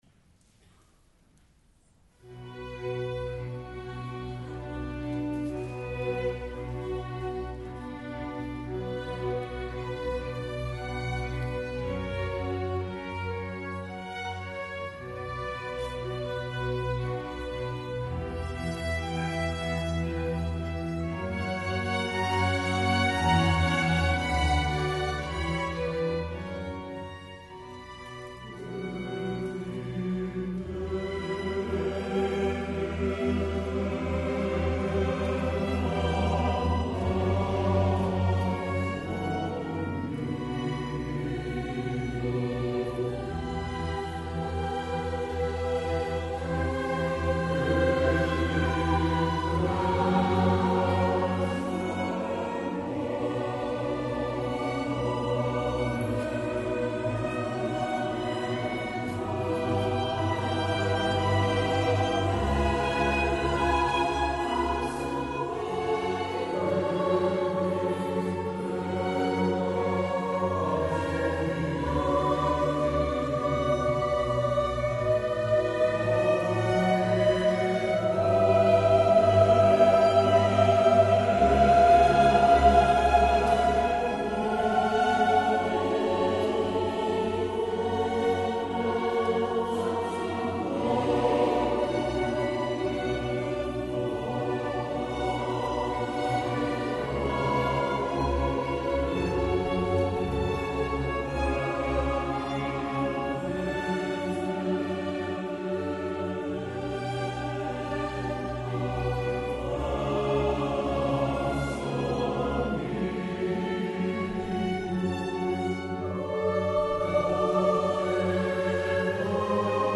Convento dell’Annunciata
Il  programma "Note di Natale" si è chiuso  con l'Orchestra dell'Assunta di Milano  e la Corale San Gaudenzio di Gambolò che hanno eseguito  il Gloria di Vivaldi e brani di Albinoni e Rodrigo.
in formato audio MP3 di alcuni branii  del concerto